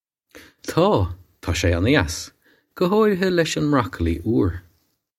Taw. Taw shay onna-yass, guh haw-ree-ha lesh on mrok-alee oor.
This is an approximate phonetic pronunciation of the phrase.